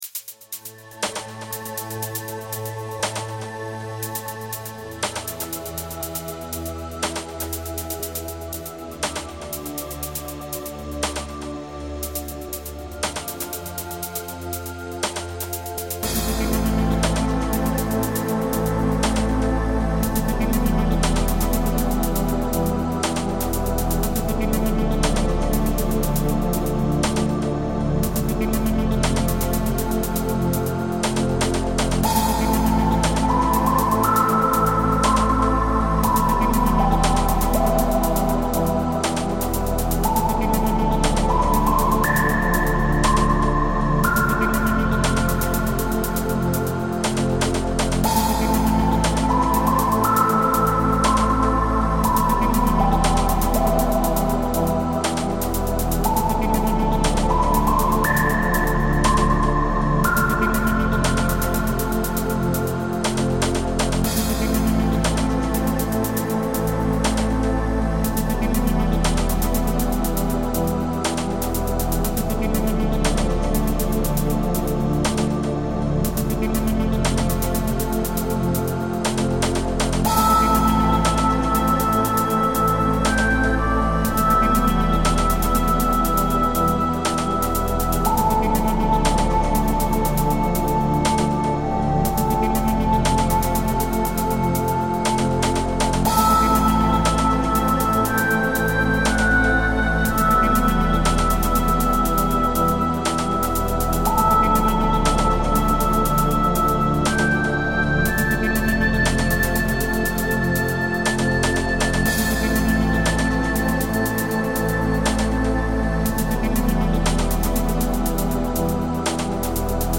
Desires Nach wie vor mein Lieblingsstück, trancig